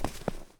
snow_0.ogg